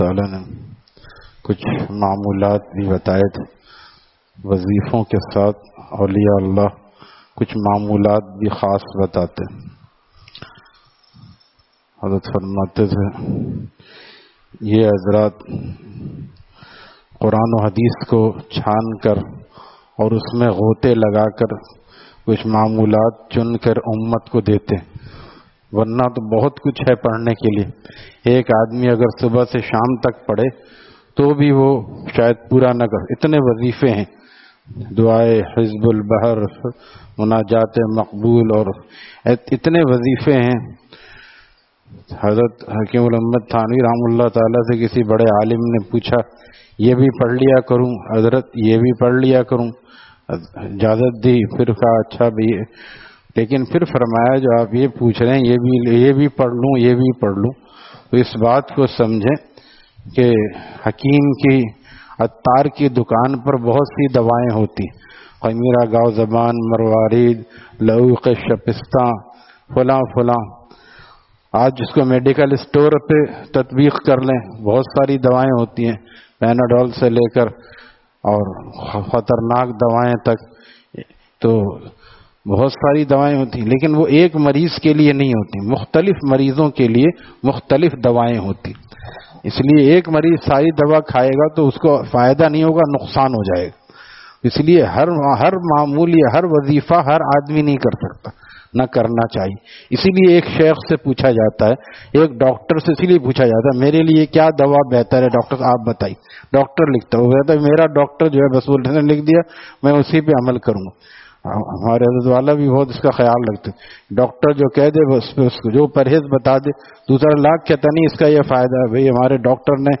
Taleem After Fajar at Jamia Masjid Gulzar e Muhammadi, Khanqah Gulzar e Akhter, Sec 4D, Surjani Town